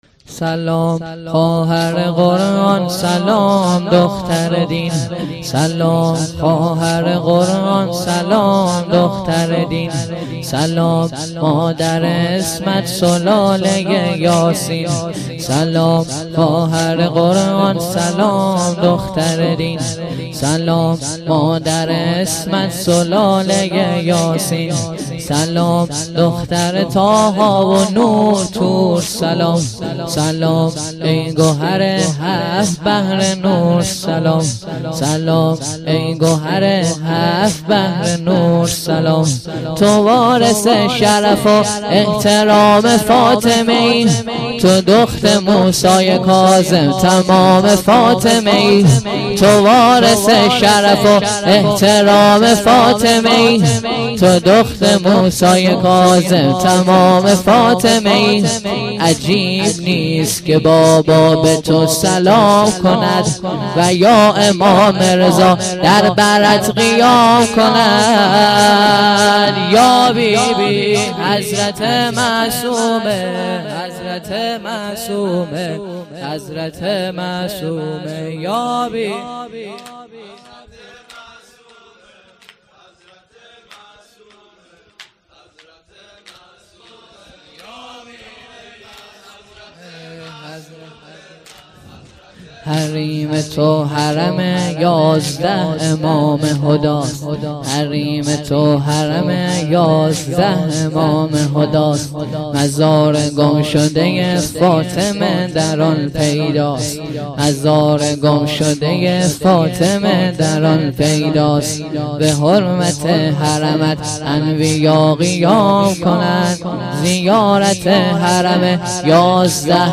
شهادت حضرت معصومه(س)1395شب اول
شور - تو که امیر نعم الامیری
واحد - محبوبه حی داور یا بنت موسی بن جعفر